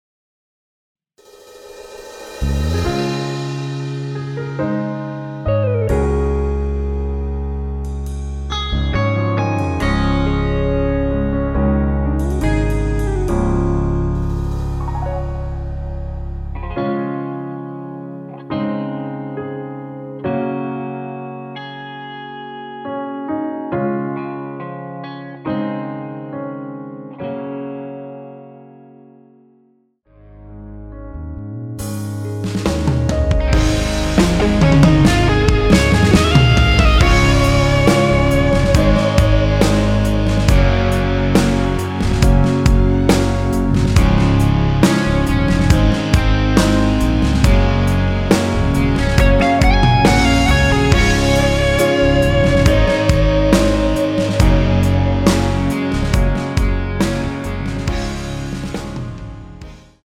원키에서(-3)내린 MR입니다.
Db
앞부분30초, 뒷부분30초씩 편집해서 올려 드리고 있습니다.